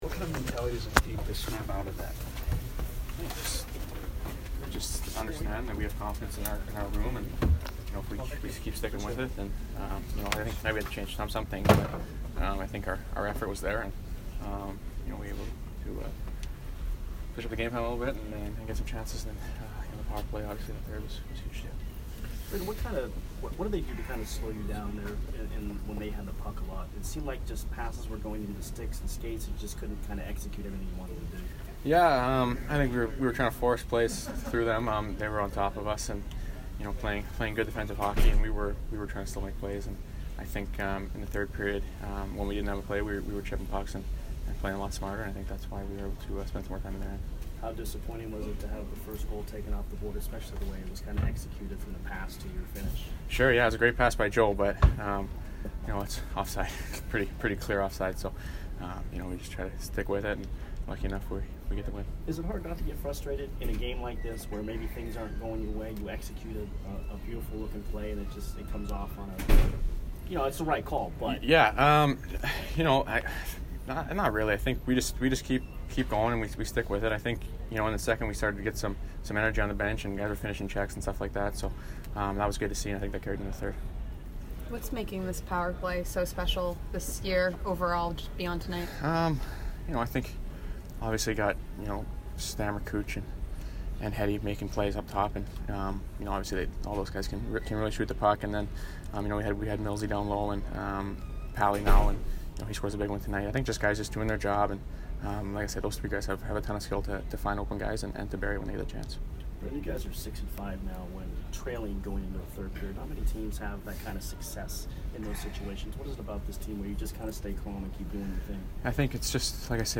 Brayden Point post-game 1/10